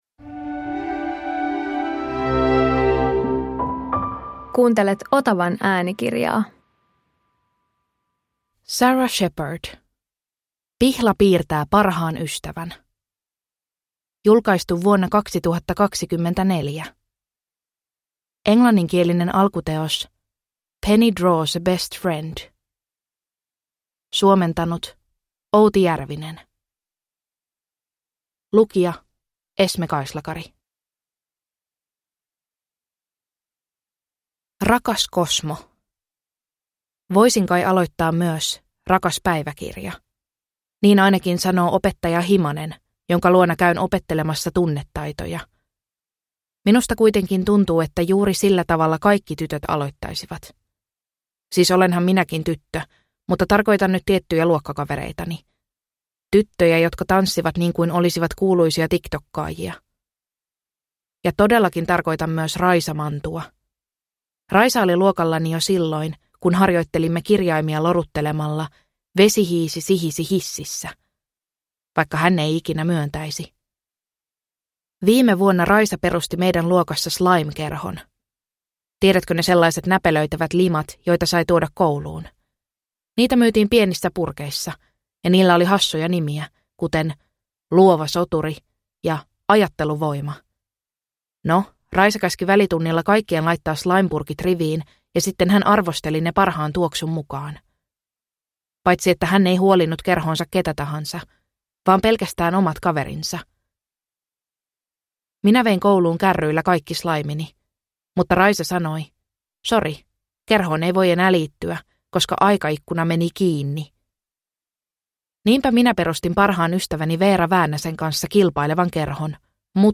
Pihla piirtää parhaan ystävän – Ljudbok